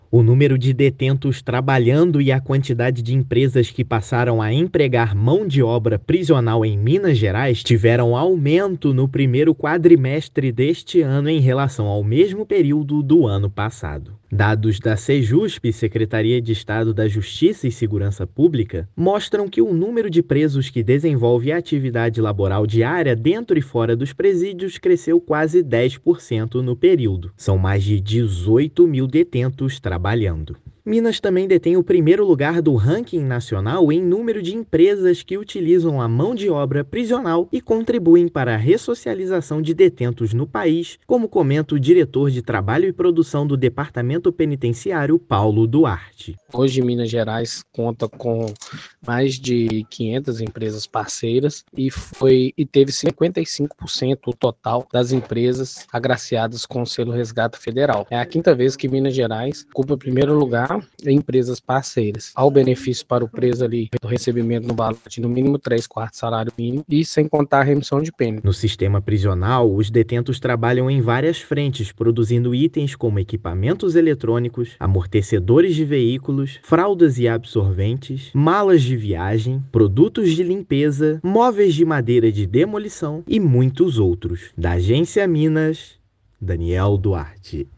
[RÁDIO] Balanço aponta crescimento de quase 10% no número de presos trabalhando
Minas também alcançou primeiro lugar nacional em número de empresas que apostam na mão de obra prisional; exposição de produtos e premiação de parceiros celebram marco. Ouça a matéria de rádio: